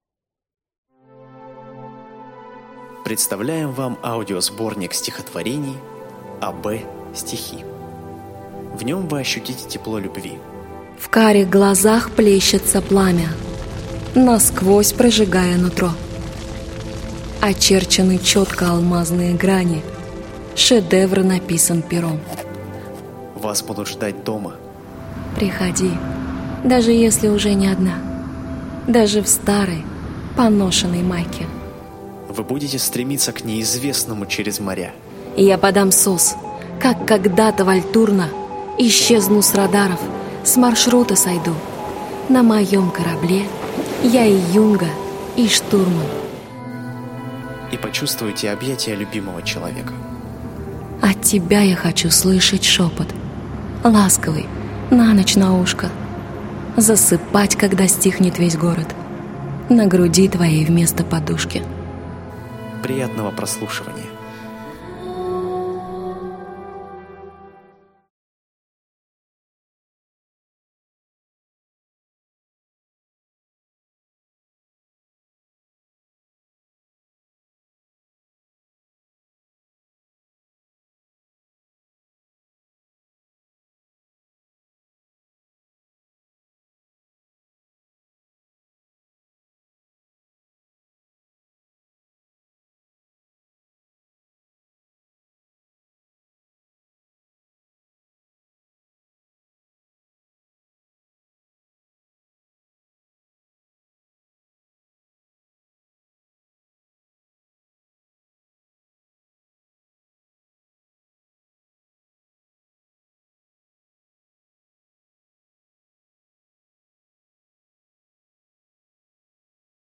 Aудиокнига АБ-стихи Автор Ася Бузаева Читает аудиокнигу Центр звукового дизайна ЗВУКАРНЯ.